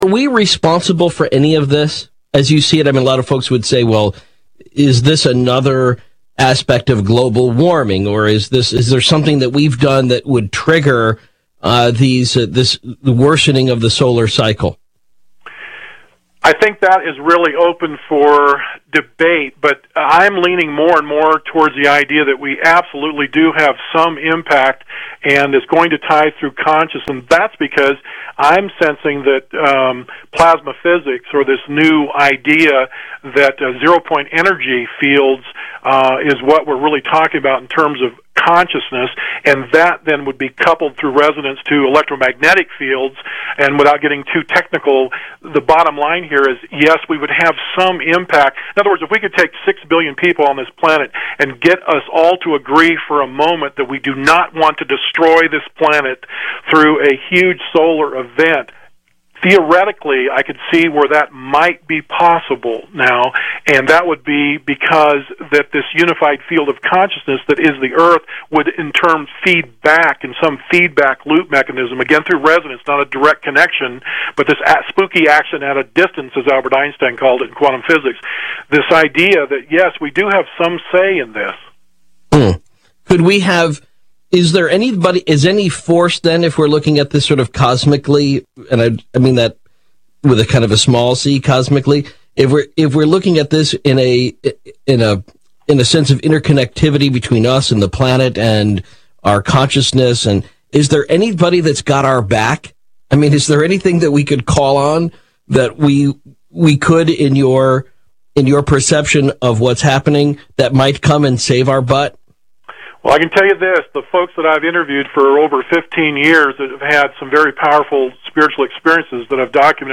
dance/electronic
House
UK-garage
Techno